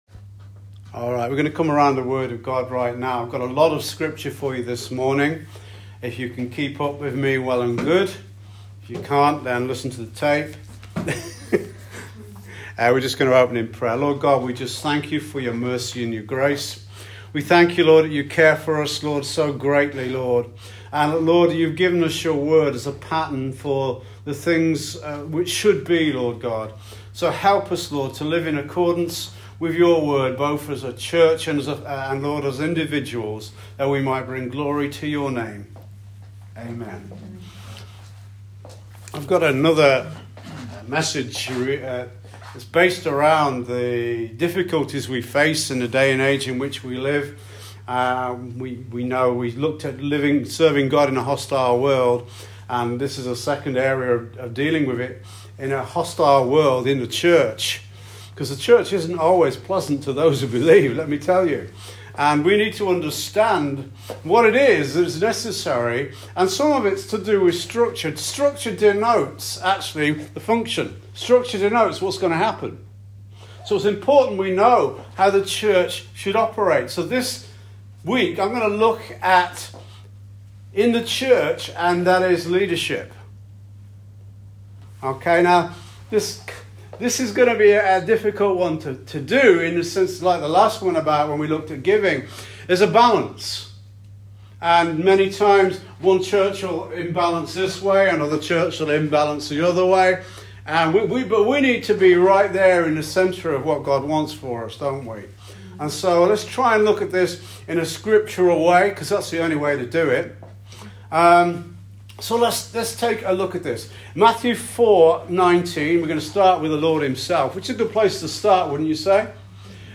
SERMON “BIBLICAL LEADERSHIP”